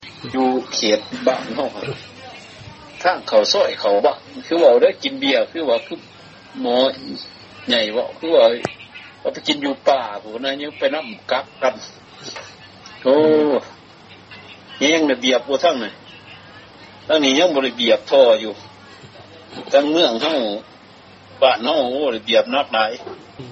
ສຽງສໍາພາດ ປະຊາຊົນເຂດເມືອງປາກຊ່ອງ ແຂວງຈໍາປາສັກ